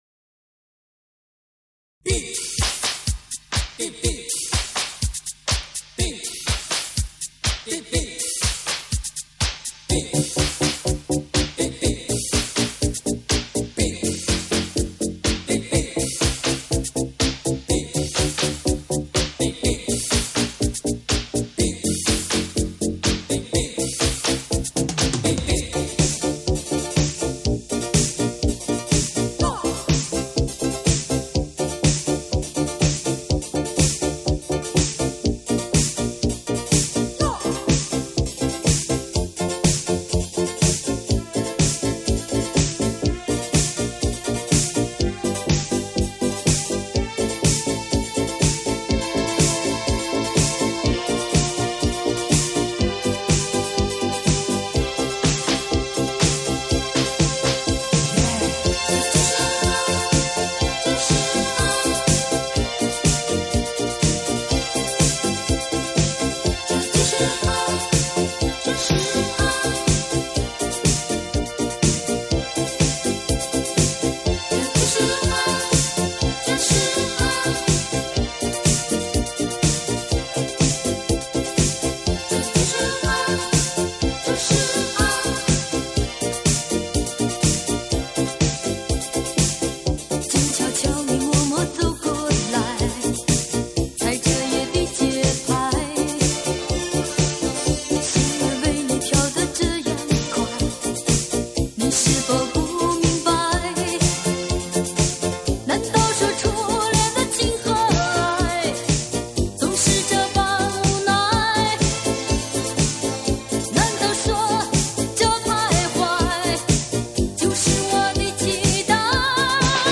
（因为试听单个文件太大，故压缩了一下）